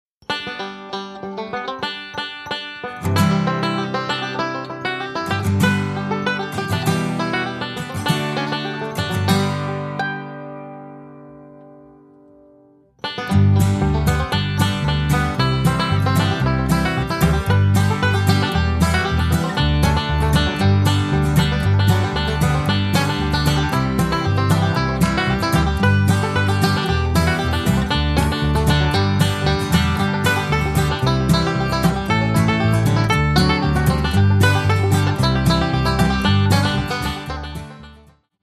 --folk-bluegrass music